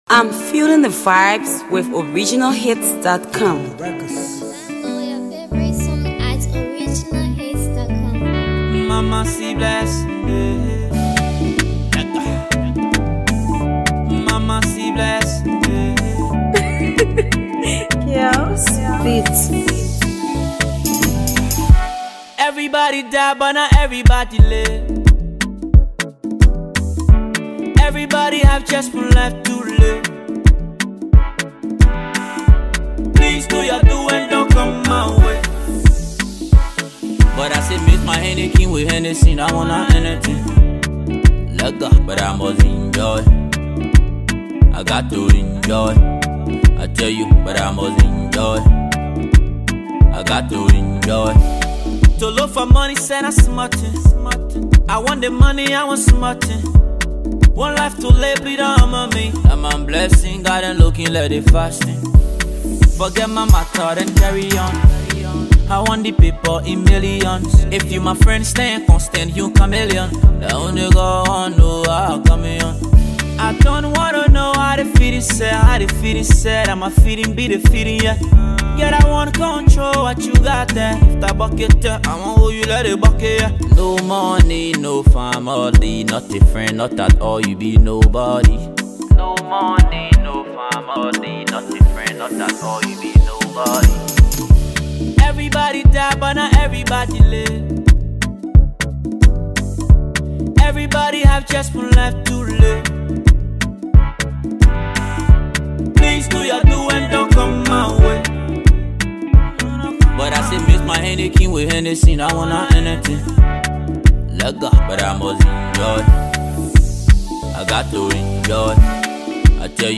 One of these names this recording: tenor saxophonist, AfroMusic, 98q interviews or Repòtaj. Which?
AfroMusic